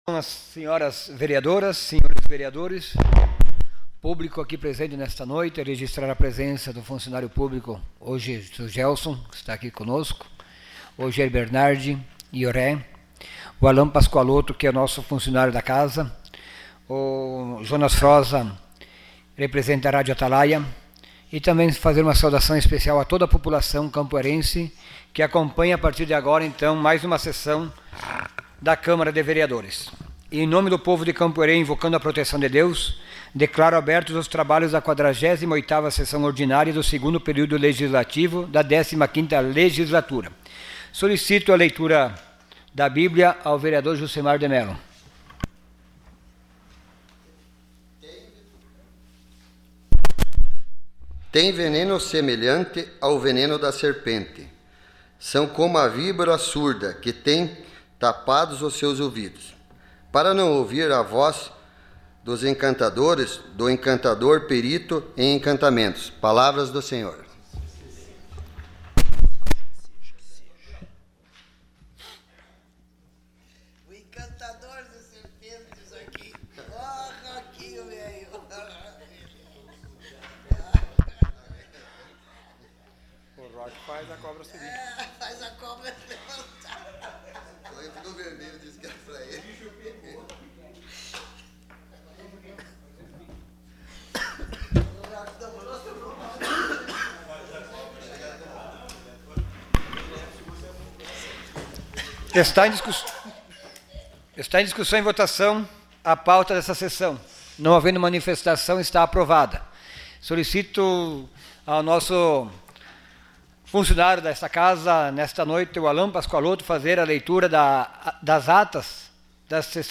Sessão Ordinária dia 18 de outubro de 2018.